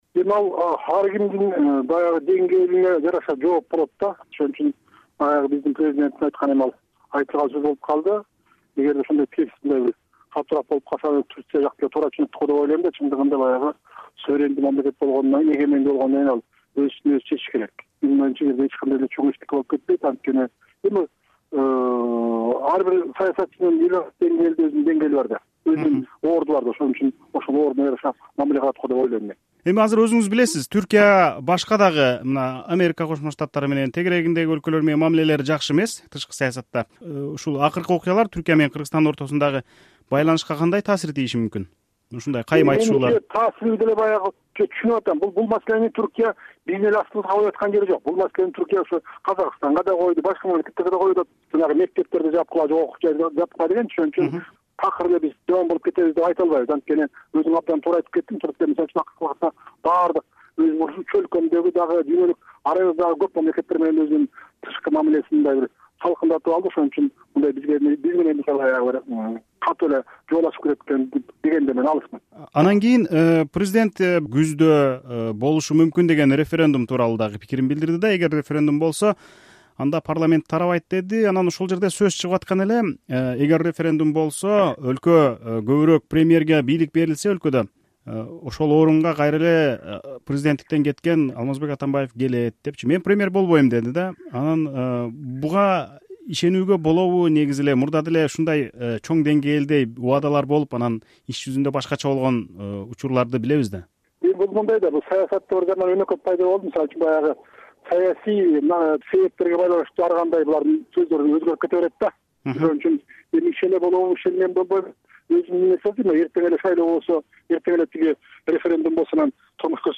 1-августта Ысык-Көлдөгү “Рух-Ордо” комплексинде президент Алмазбек Атамбаев журналисттерге маалымат жыйынын куруп, дүйнөдөгү жана өлкөдөгү акыркы окуялар боюнча позициясын билдирди.